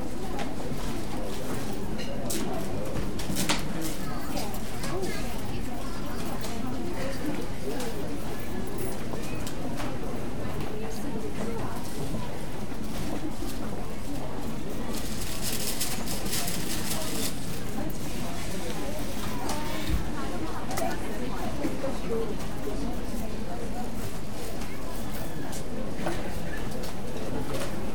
store.ogg